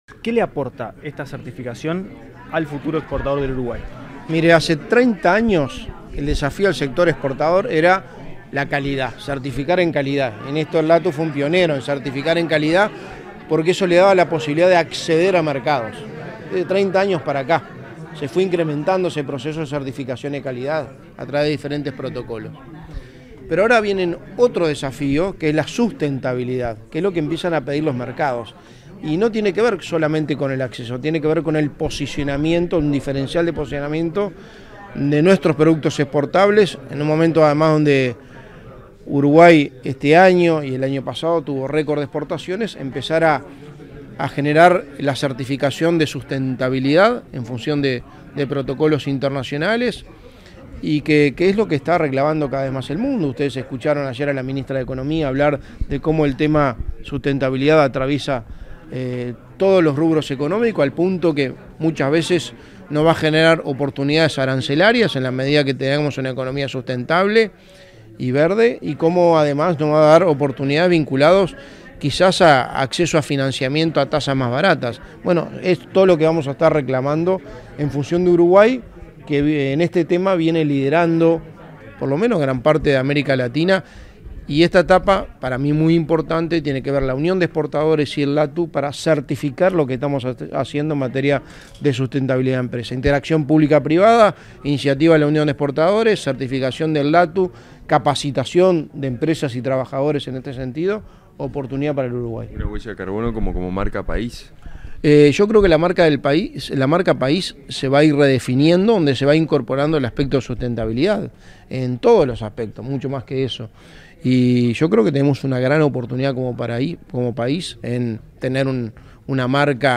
Declaraciones a la prensa del secretario de la Presidencia, Álvaro Delgado
Tras participar en la firma de convenio entre el LATU y la Unión de Exportadores del Uruguay, este 27de julio, para impulsar la Unidad de Exportación